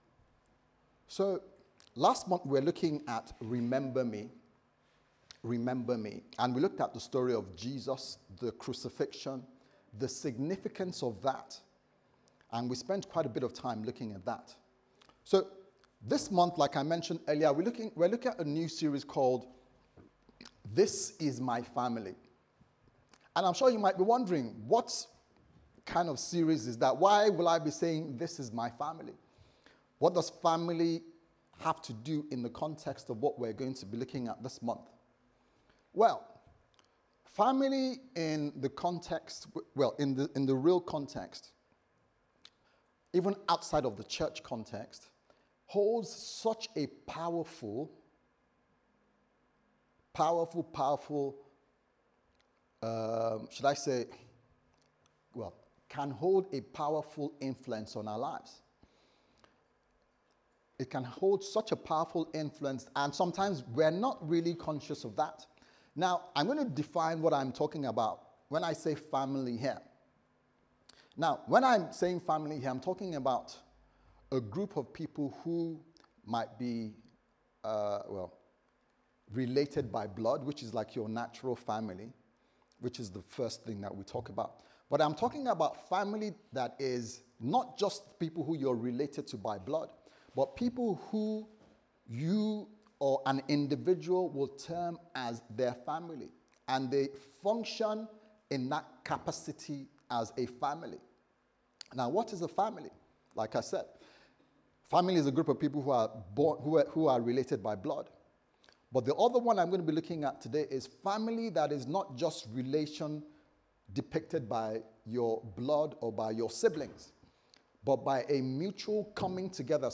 This Is My Family Service Type: Sunday Service Sermon « And The Thief Said To Jesus